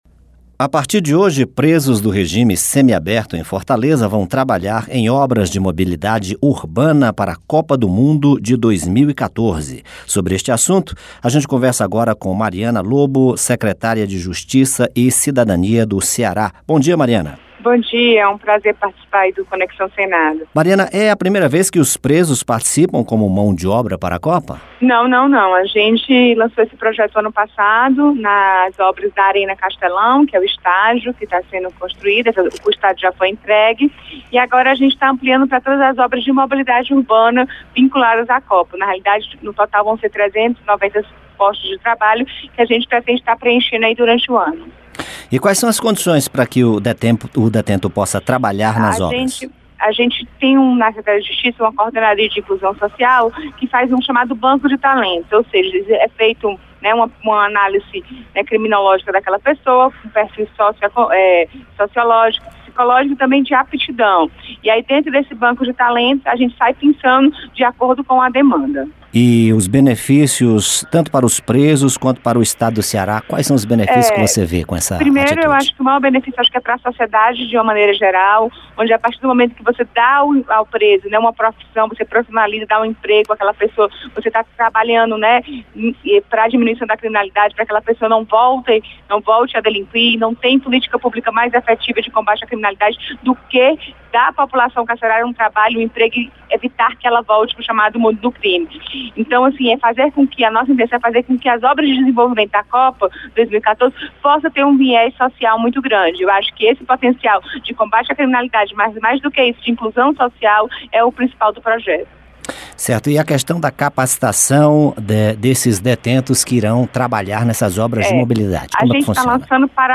Presos do regime semiaberto em Fortaleza vão trabalhar em obras de mobilidade urbana para Copa do Mundo. Entrevista com a secretária de Justiça e Cidadania do Ceará, Mariana Lobo.